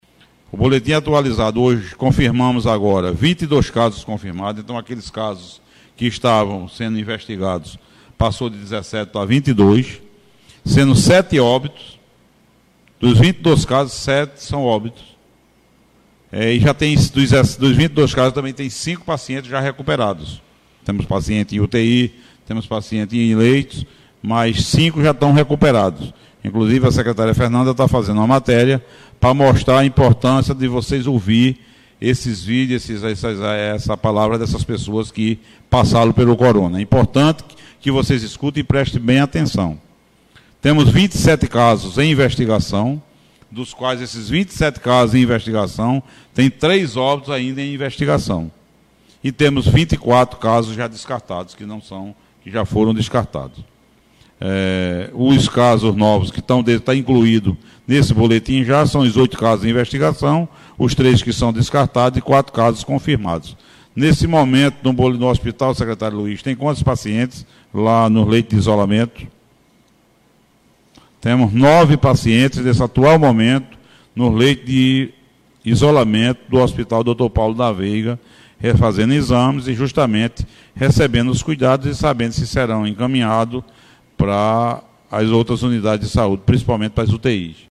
As informações foram repassadas pelo prefeito Joaquim Neto (PSDB) durante coletiva de imprensa na noite desta segunda-feira (11).